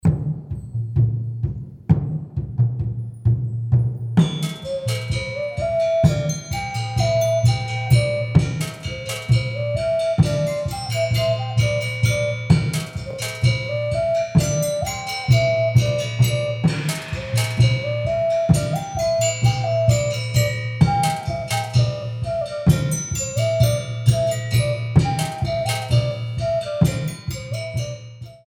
nine beats